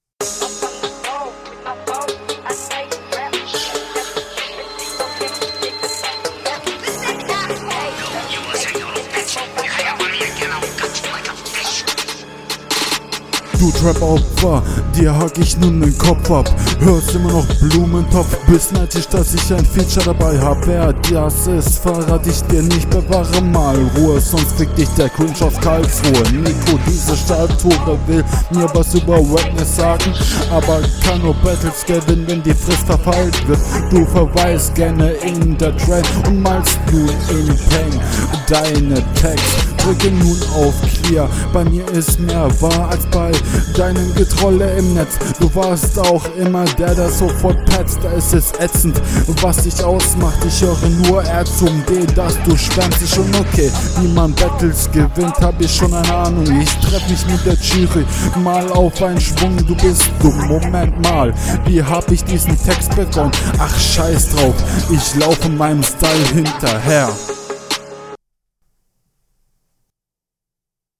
Hast deutlich mehr Reime in deiner Runde Flow ist auch da hin und wieder mal …